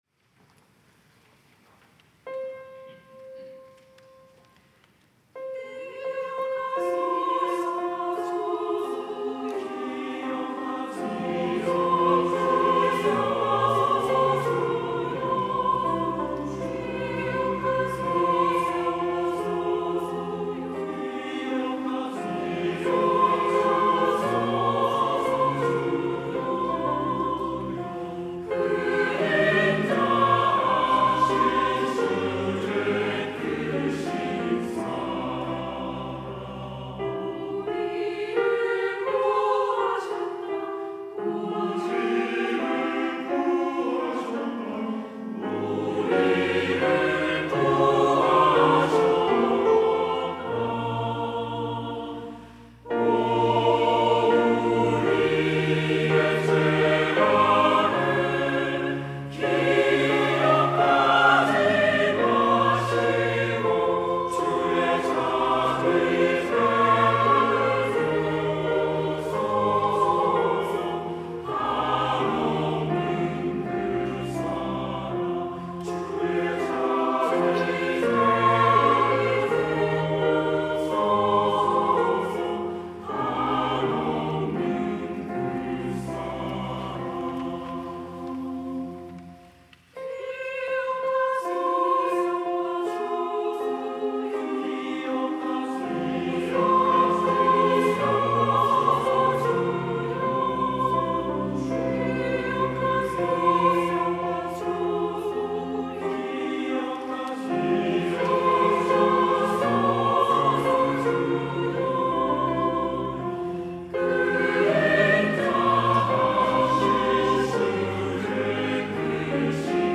찬양대 시온